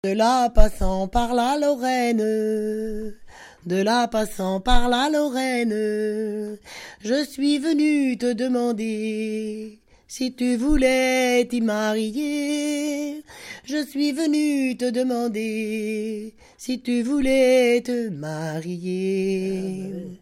Mémoires et Patrimoines vivants - RaddO est une base de données d'archives iconographiques et sonores.
Genre dialogue
Pièce musicale inédite